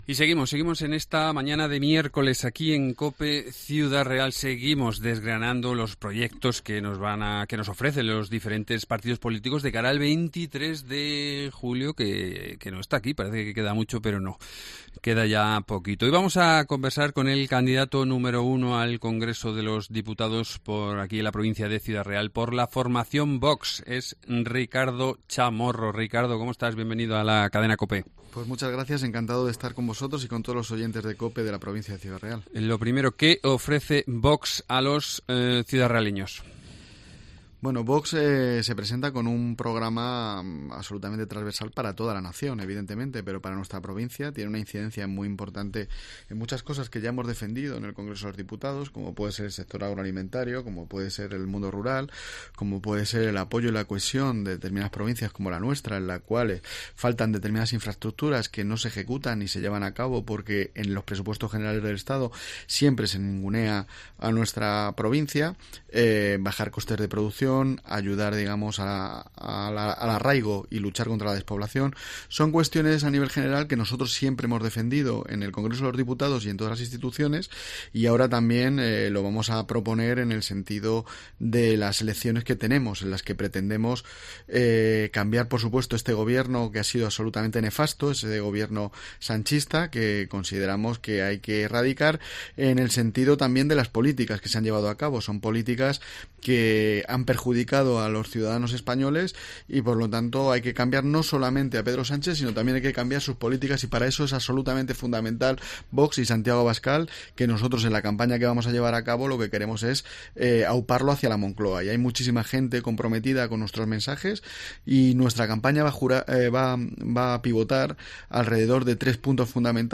Entrevista
Ricardo Chamorro, candidato número 1 de Vox al Congreso en la provincia de Ciudad Real